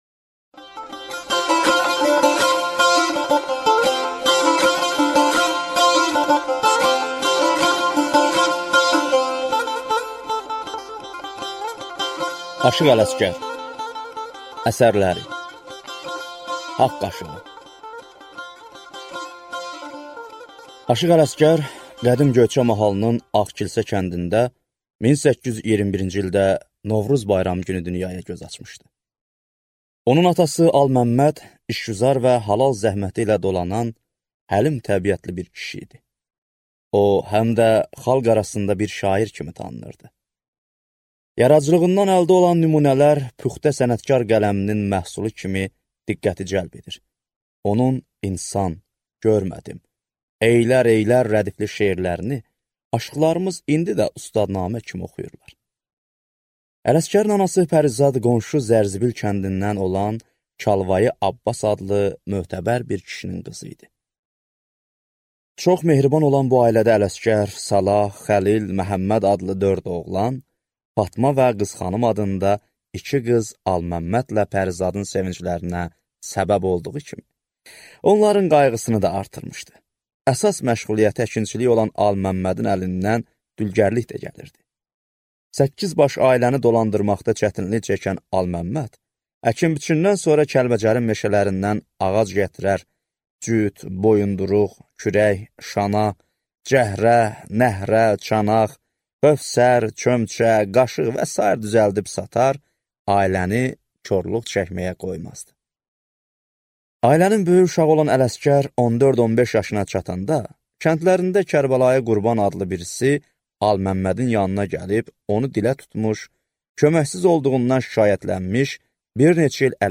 Аудиокнига Aşıq Ələsgərin əsərləri | Библиотека аудиокниг